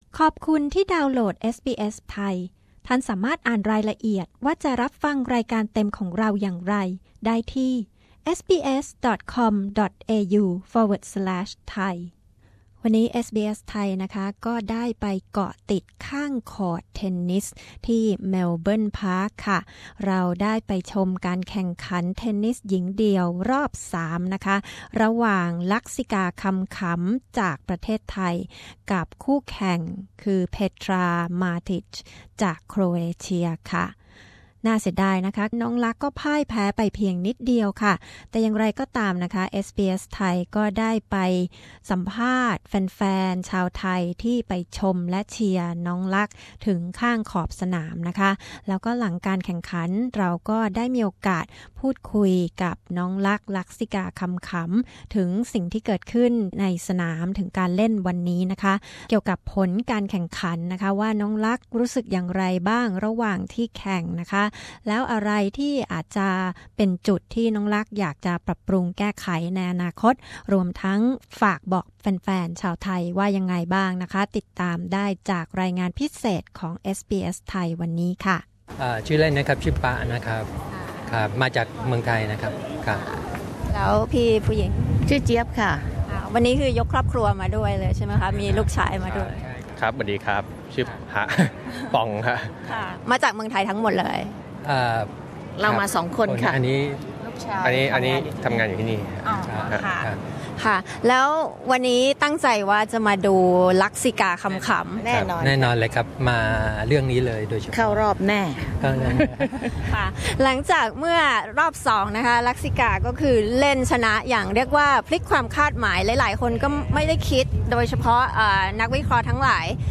ลักษิกา คำขำ เผยอะไรทำให้พ่ายคู่แข่งไปในการแข่งขันรอบ 3 ทั้งที่เล่นได้อย่างสูสี ในวันนี้ (19 ม.ค. 61) ที่สนามรอด เลเวอร์ อารีน่า พร้อมฟังเสียงคนไทยที่มาเชียร์สาวนักเทนนิสไทยถึงในสนาม